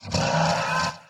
Sound / Minecraft / mob / horse / skeleton / idle3.ogg